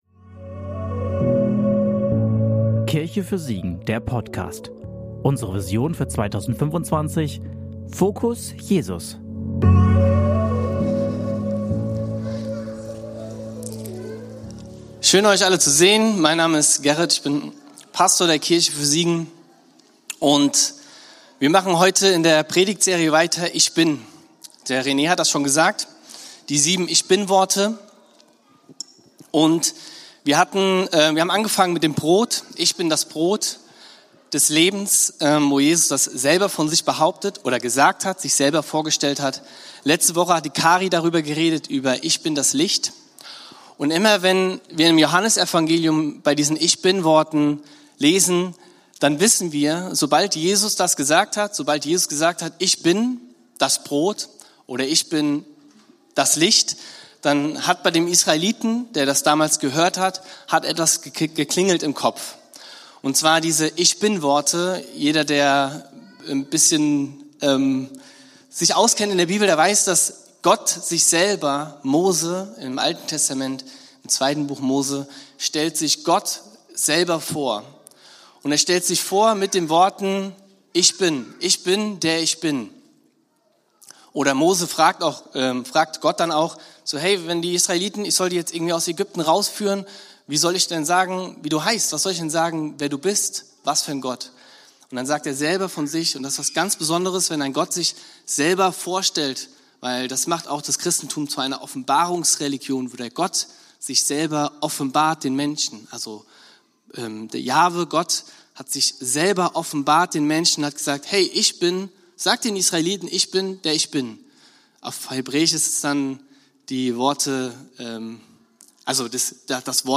Predigt vom 31.08.2025 in der Kirche für Siegen